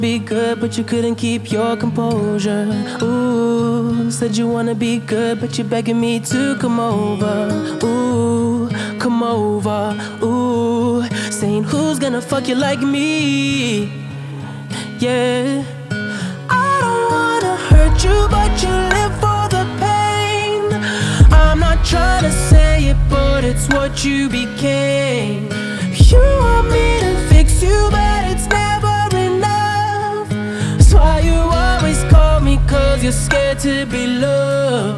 Жанр: R&B / Соул